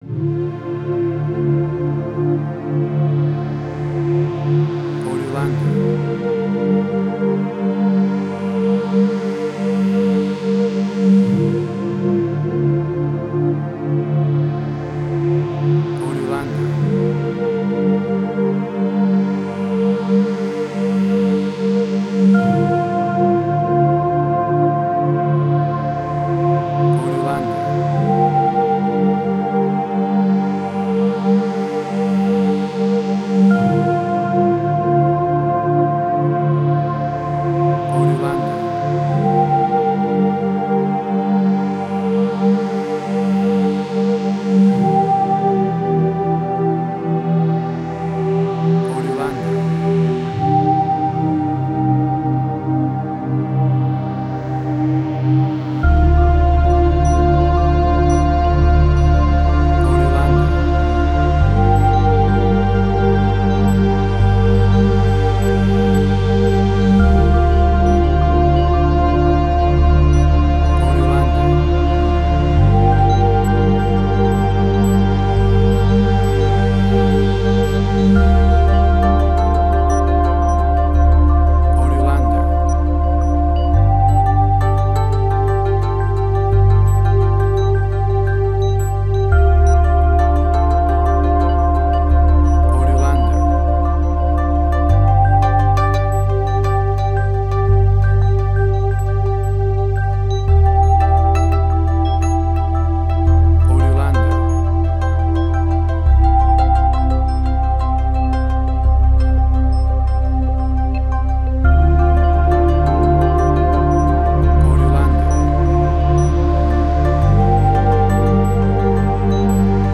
New Age.
emotional music
Tempo (BPM): 87